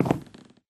Minecraft / step / wood5.ogg
wood5.ogg